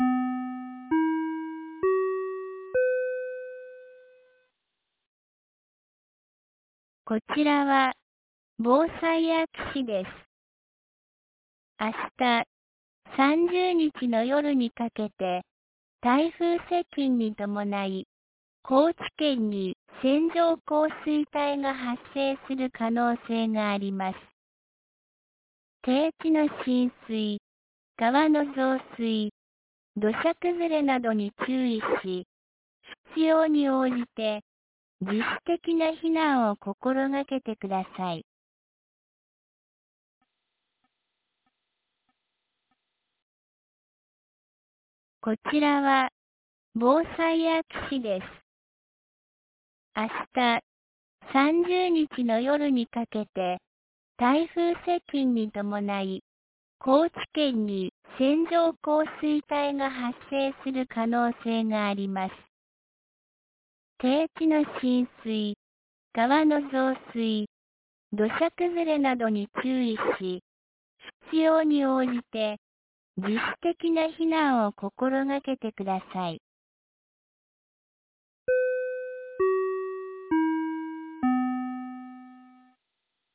2024年08月29日 15時41分に、安芸市より畑山、下尾川へ放送がありました。